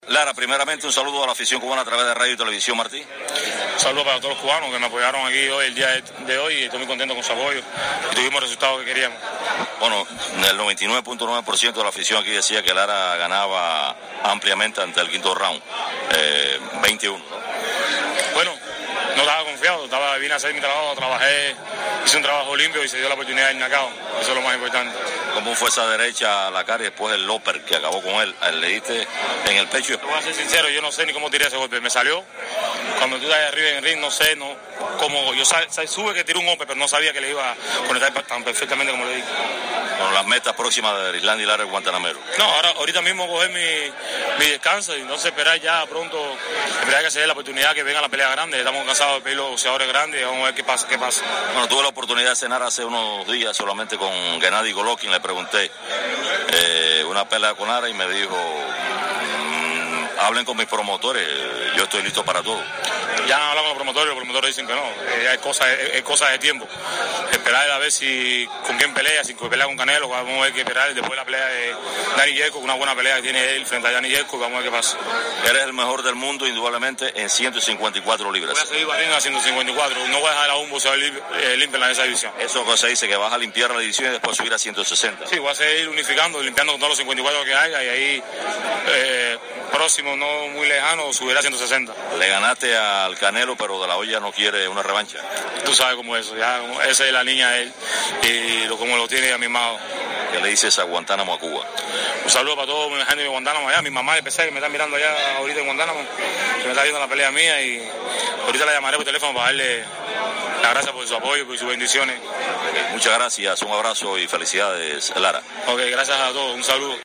Al concluir la pelea en el Hialeah Park Racing & Casino Martí Noticias conversó con el ídolo de Guantánamo.
Erislandy Lara, entrevistado